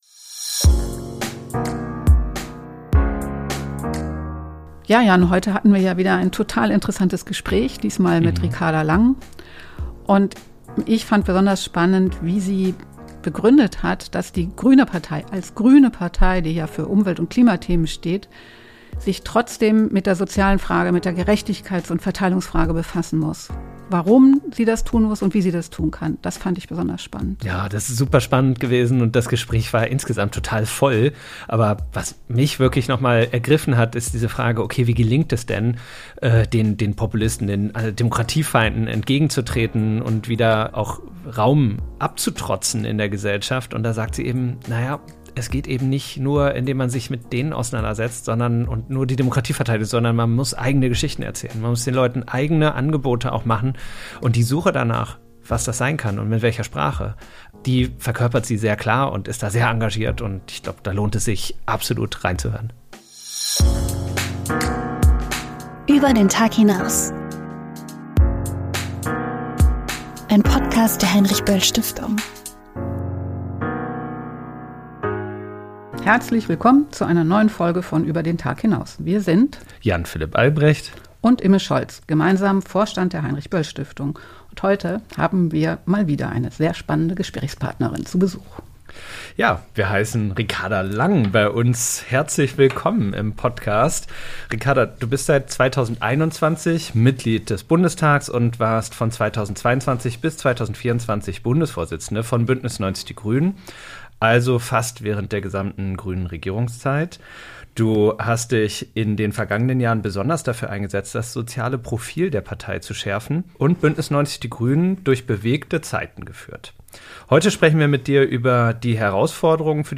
Wie können die Grünen nach der Ampel verlorenes Vertrauen zurückgewinnen, die Rolle des Status-Quo-Verteidigers überwinden und progressive Politik ohne Verzicht buchstabieren? Ein Gespräch mit Ricarda Lang.